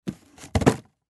Звуки картонной коробки
Пустую коробку оставили на столе